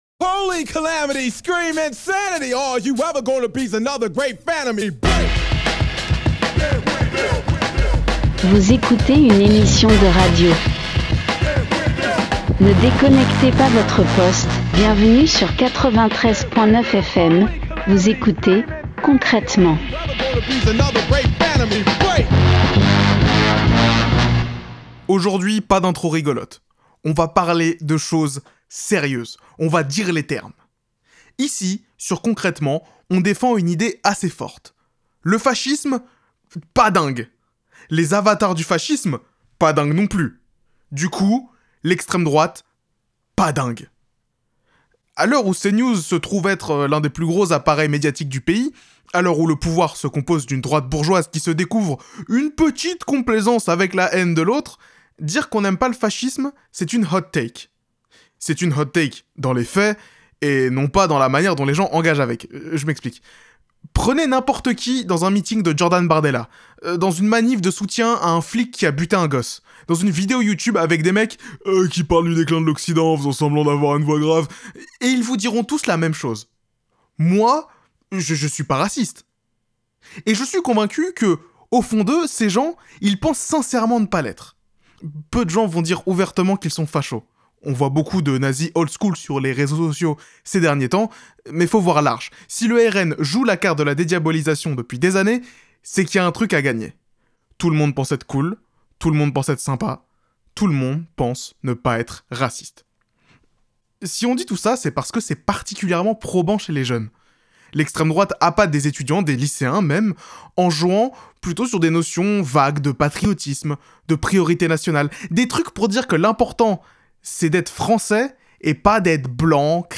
Partager Type Création sonore Société vendredi 26 avril 2024 Lire Pause Télécharger Aujourd'hui pas d'intro, pas de petite blague, pas de demi-mots : juste 20 minutes de radio sur la montée du fascisme en France.